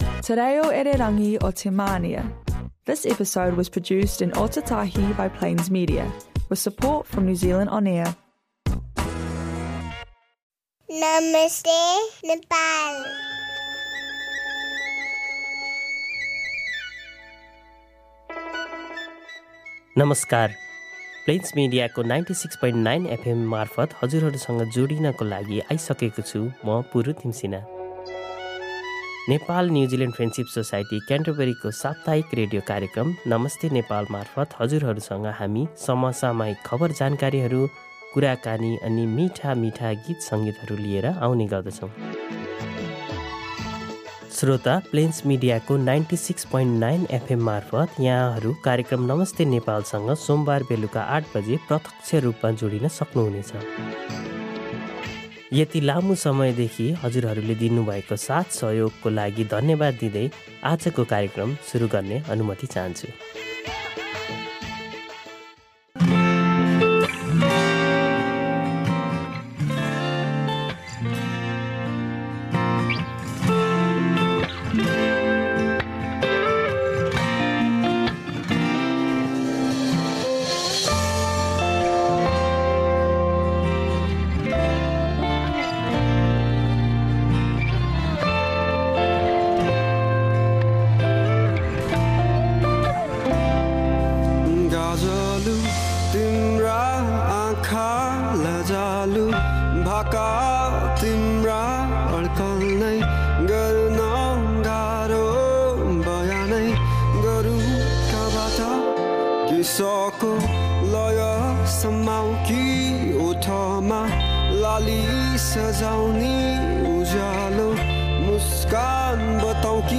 Filled with new nepali songs, the episode wraps up with some popular things to do this time of the year.